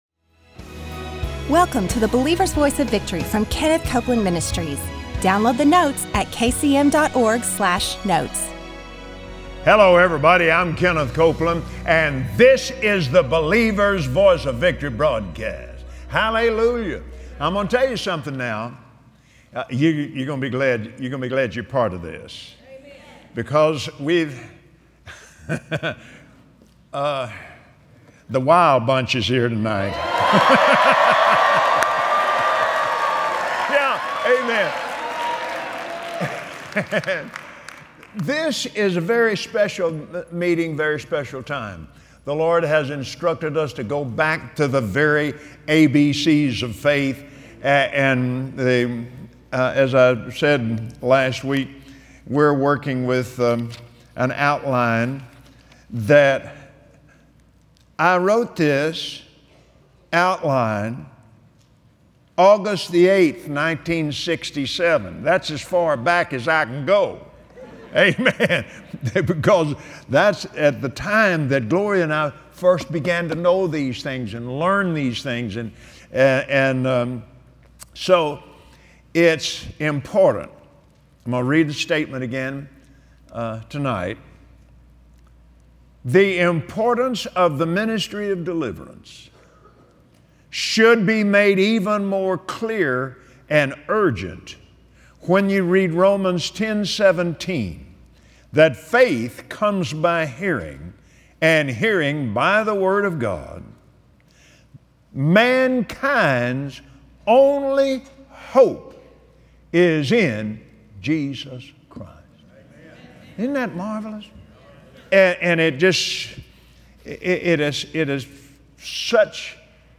Join Kenneth Copeland as he continues his teaching on the foundations of faith. Today, he reveals how faith receives and why faith is an issue of the heart.